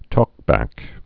(tôkbăk)